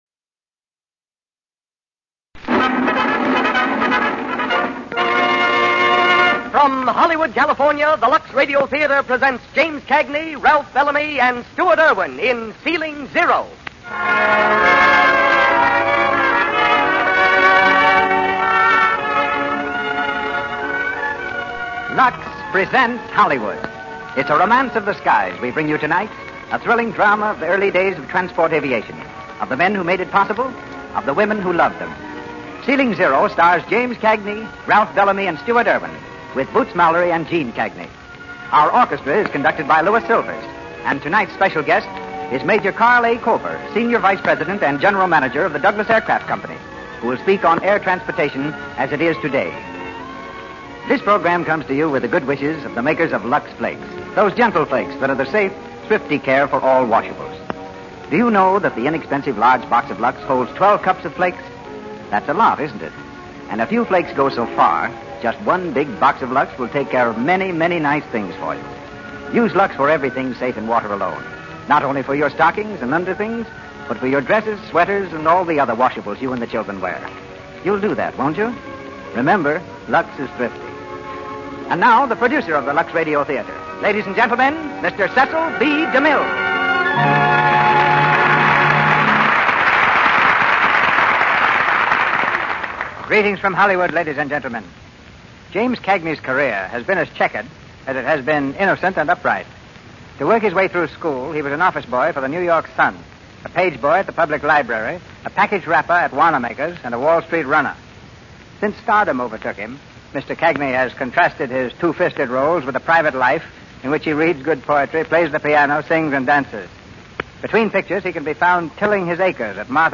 Ceiling Zero, starring James Cagney, Ralph Bellamy, Stuart Erwin